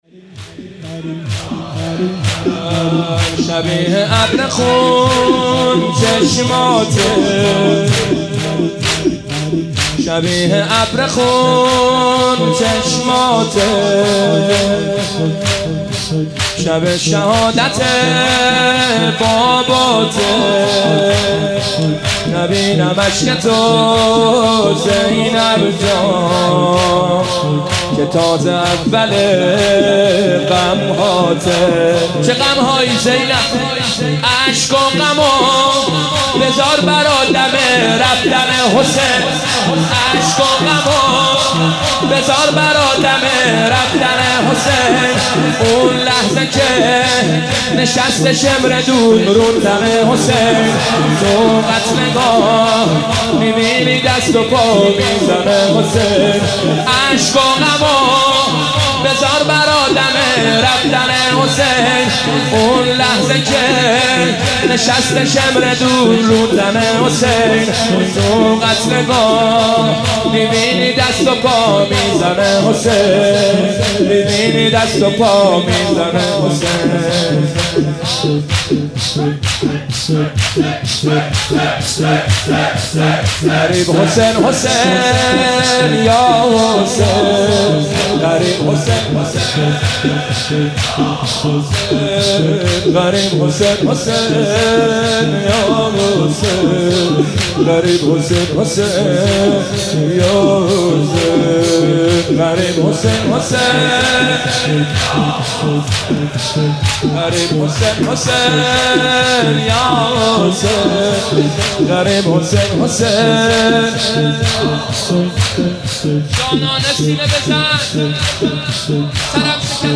مناسبت : شب بیست و یکم رمضان - شب قدر دوم
مداح : حسین سیب سرخی قالب : شور